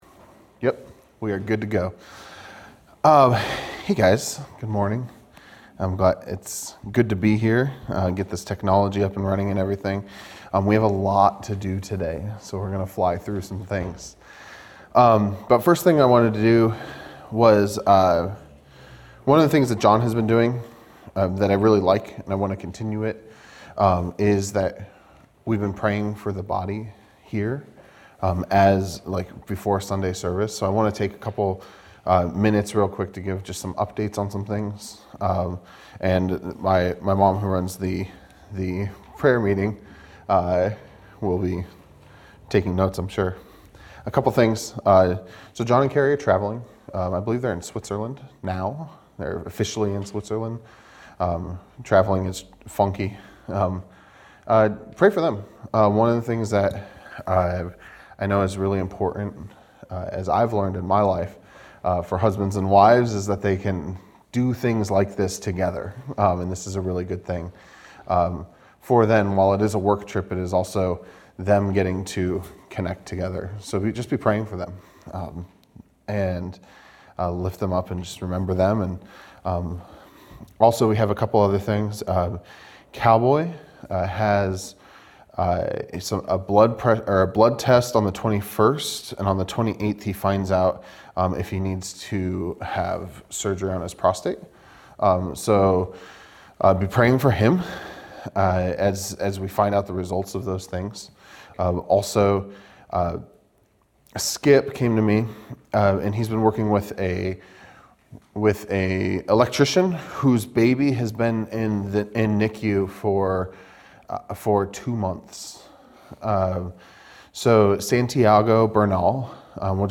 A message from the series "Matthew." Matthew 25:14-30